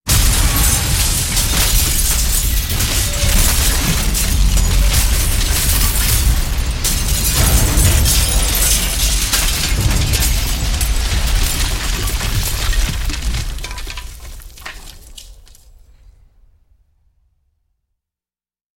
На этой странице собраны реалистичные звуки разрушения зданий: обвалы, взрывы, треск конструкций.
Продолжительный гул разрушения небоскреба